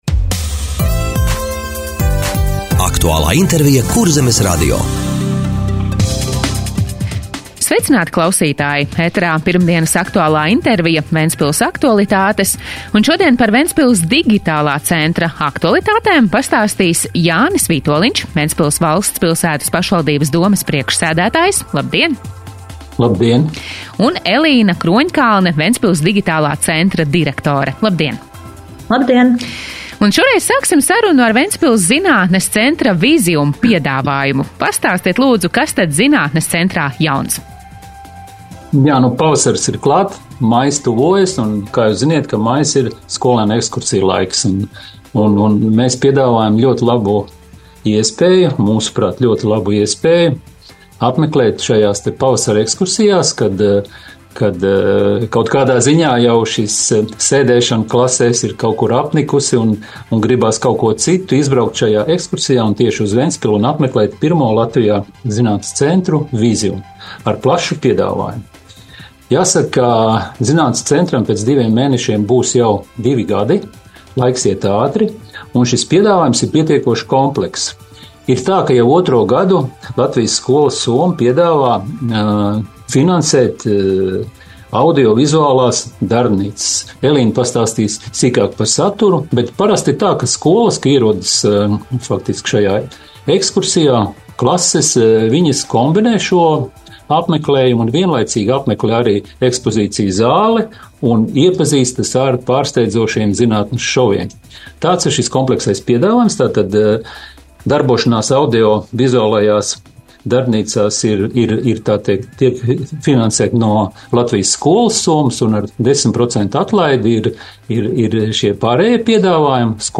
Radio saruna Ventspils Digitālā centra aktualitātes